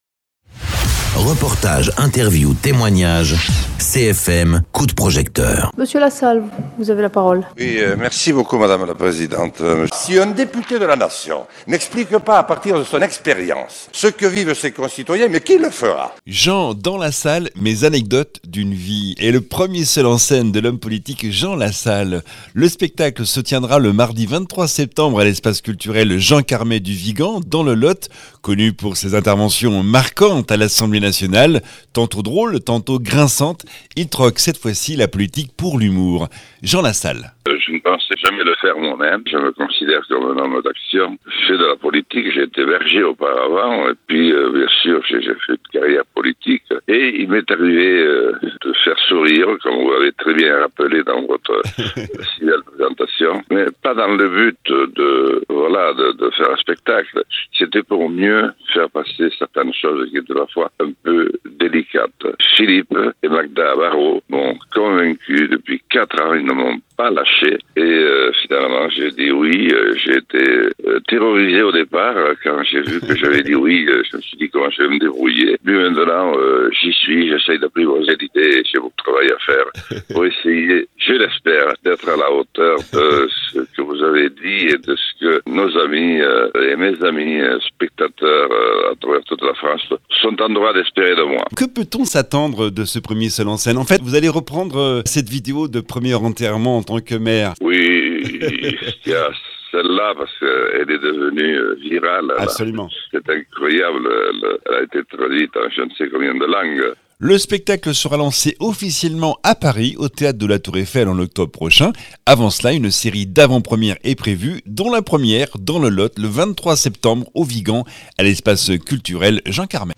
Interviews
Invité(s) : Jean LASSALLE, Ancien Maire de Lourdios-Ichère, Ex-Député, Candidat à la Présidentielle (1017 et 2022)